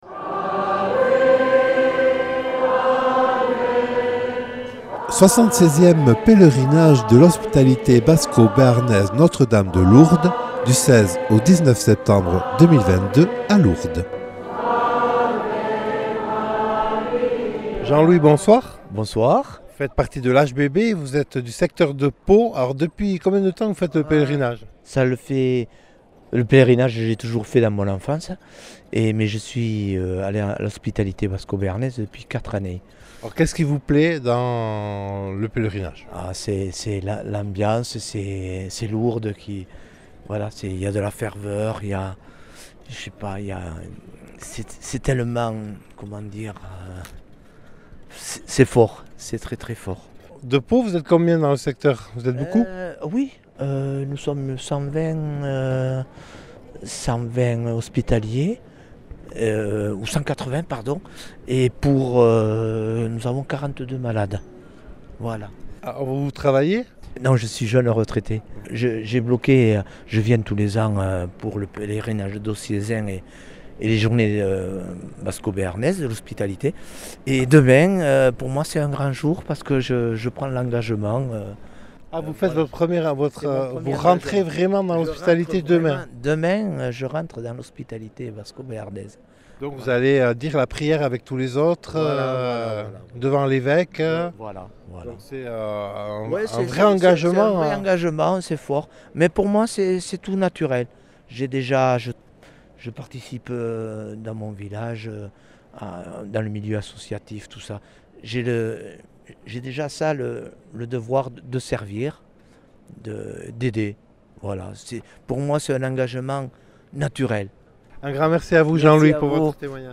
Reportage réalisé les 17 et 18 septembre 2022 lors du 76ème Pèlerinage diocésain de l’Hospitalité Basco-Béarnaise Notre-Dame de Lourdes.